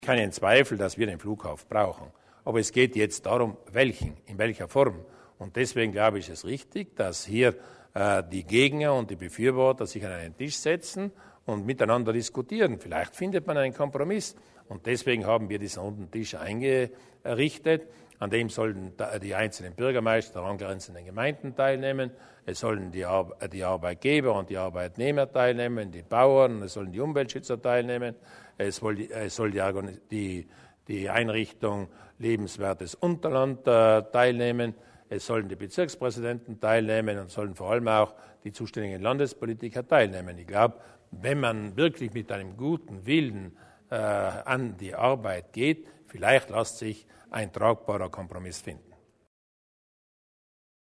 Landeshauptmann Durnwalder über die Zukunft des Flughafens in Bozen